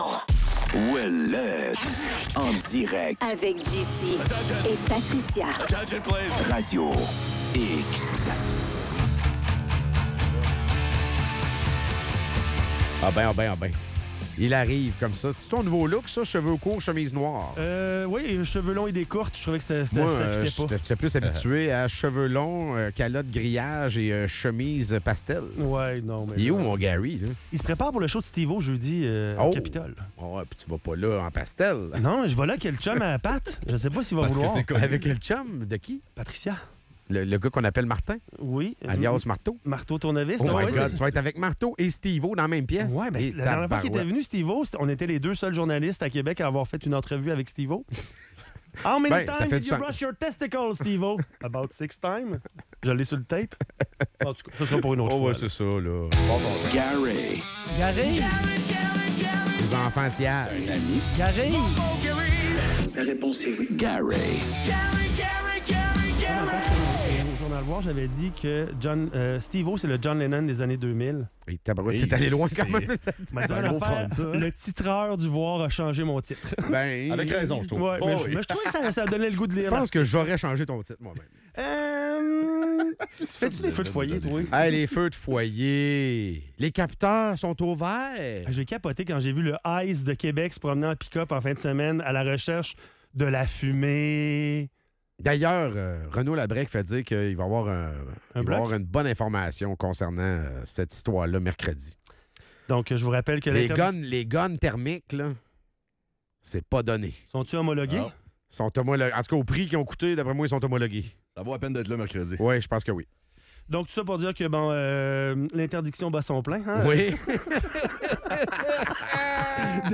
Les animateurs évoquent des capteurs d'air jugés punitifs et soulignent les incohérences dans les rapports sur la qualité de l'air.